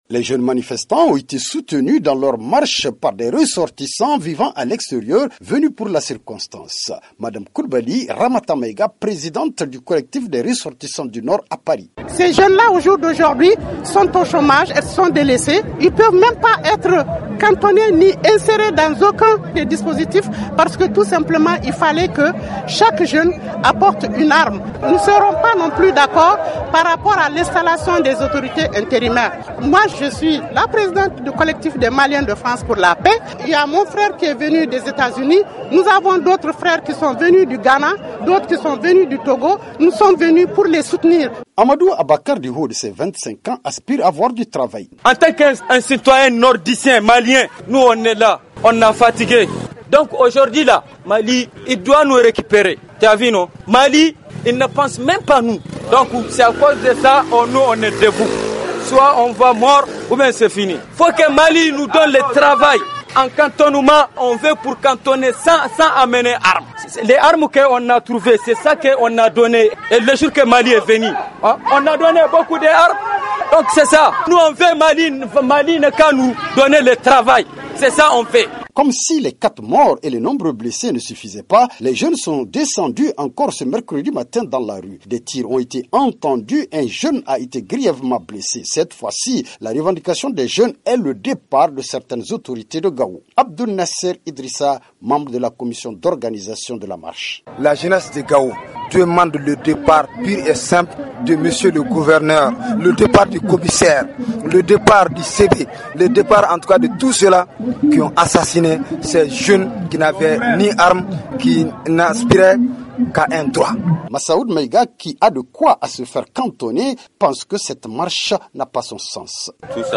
Deuxième jour de manifestations violentes à Gao-Un reportage